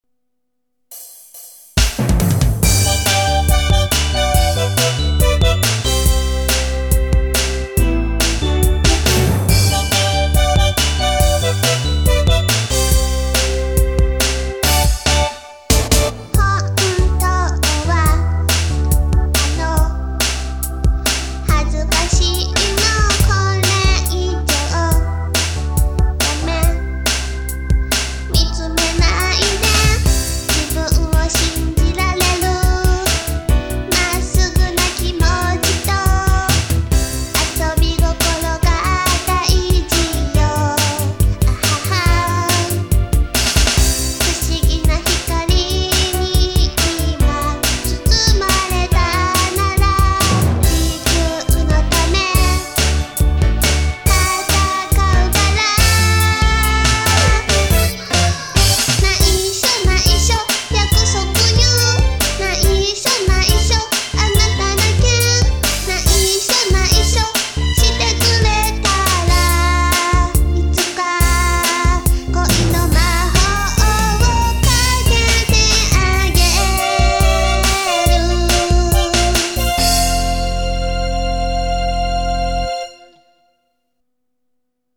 テーマソング：